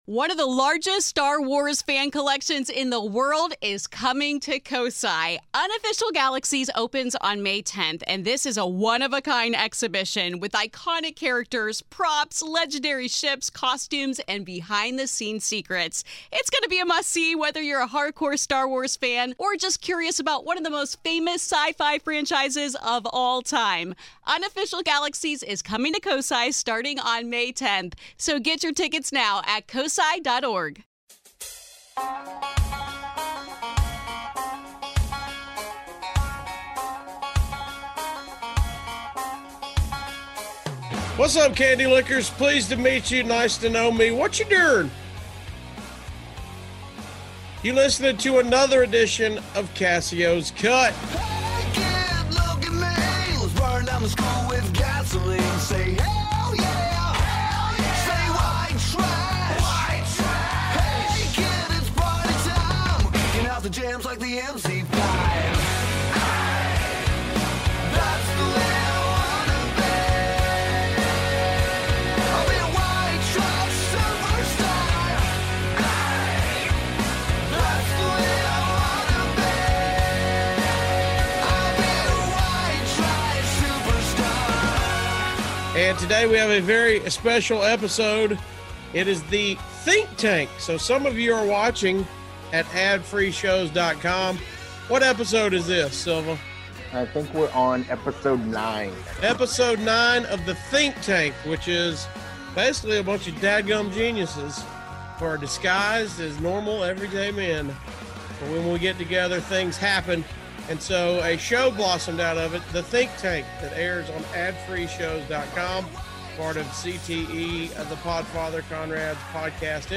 And we have 9 of my fellow Think Tank buddies with me today!